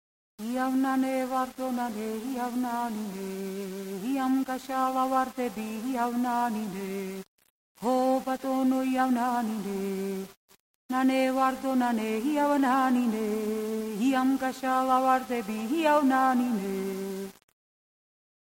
- Traditional songs of Georgian women
healing song